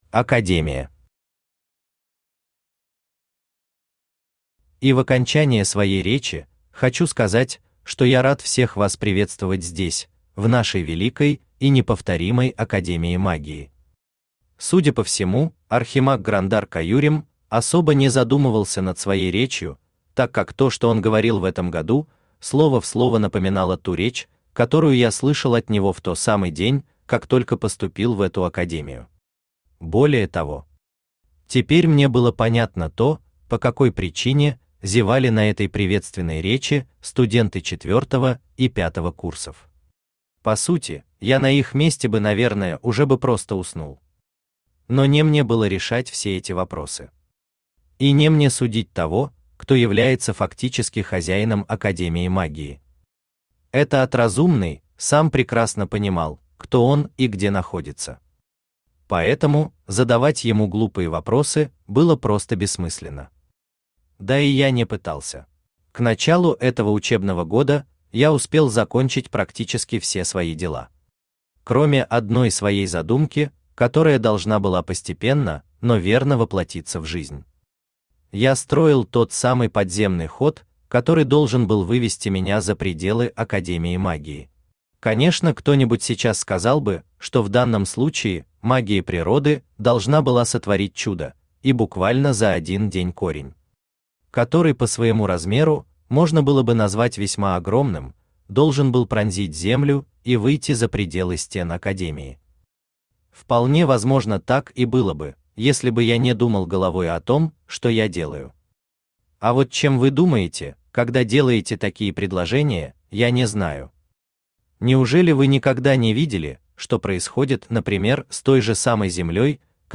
Аудиокнига Лесовик. Неожиданные сюрпризы | Библиотека аудиокниг
Неожиданные сюрпризы Автор Хайдарали Усманов Читает аудиокнигу Авточтец ЛитРес.